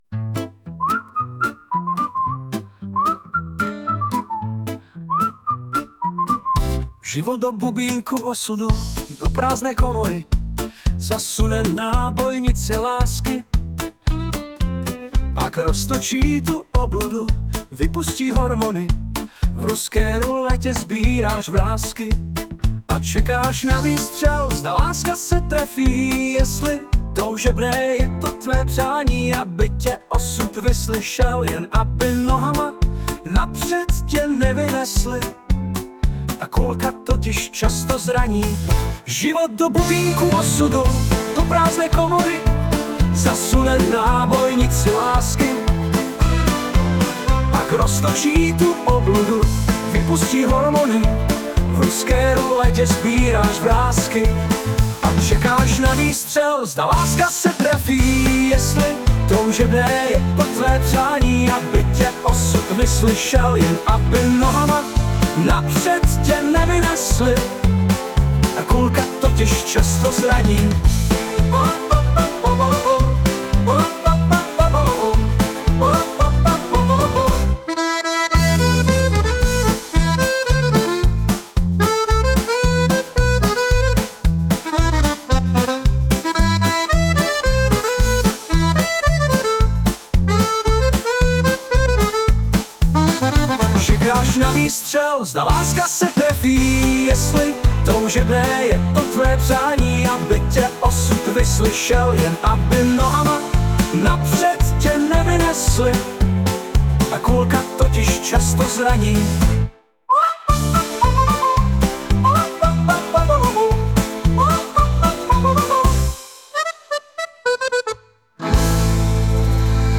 Hudba, zpěv, obraz: AI
ten rytmus mi bezděky rozpohyboval nohy - díky
Tady konkrétně russian folk.